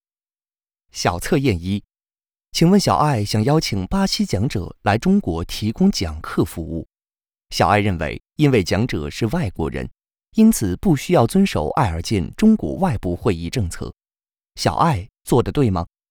Chinese_Male_005VoiceArtist_20Hours_High_Quality_Voice_Dataset